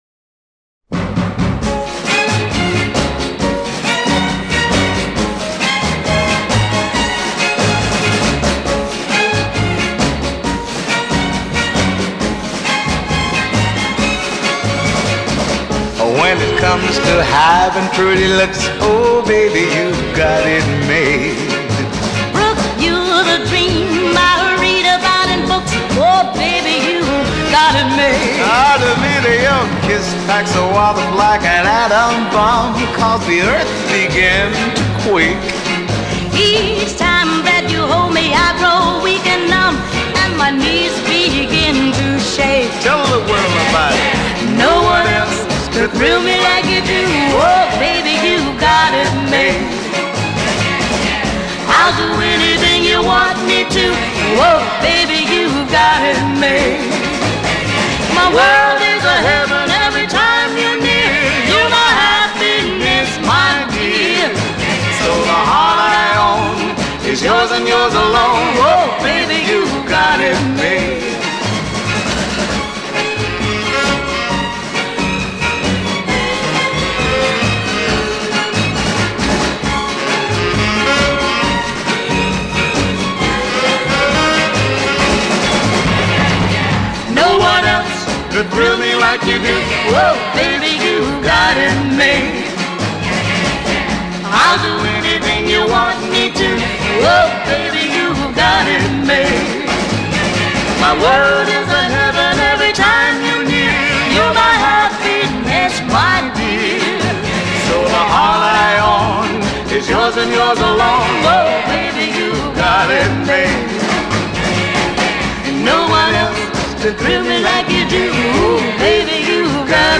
Genre: Pop
Style: Vocal